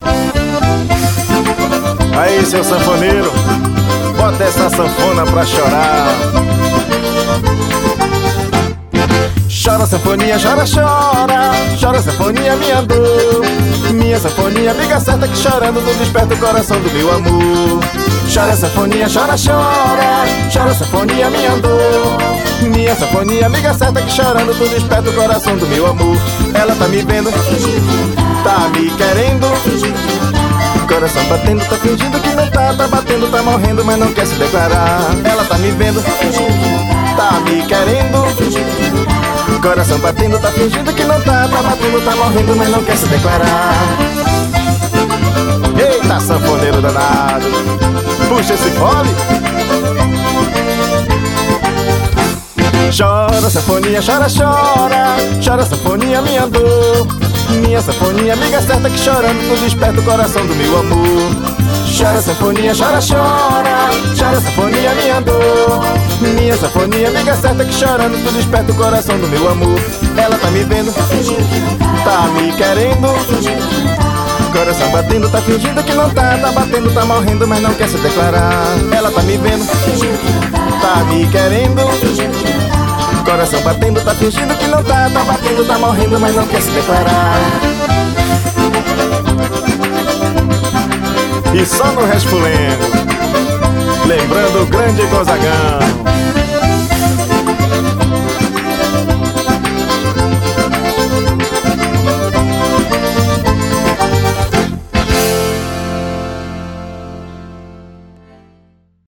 Composição: 1 Baião e 2 Xote.